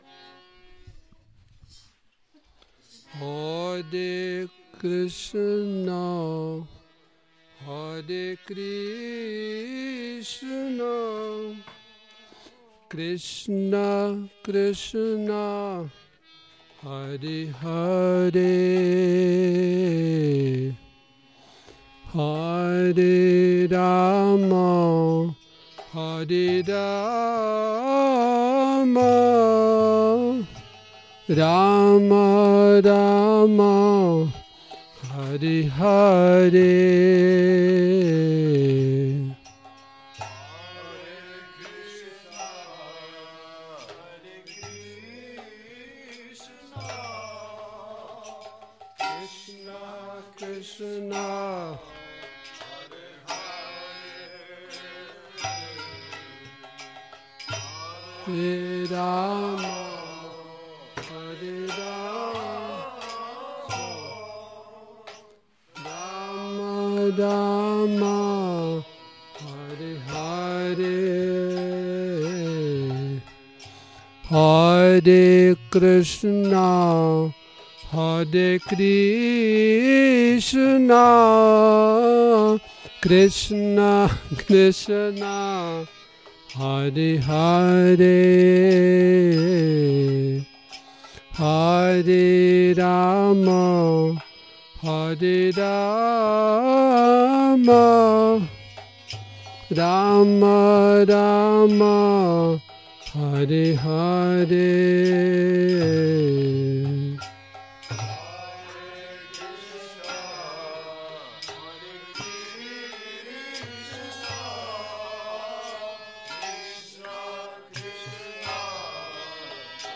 Kírtan Nedělní program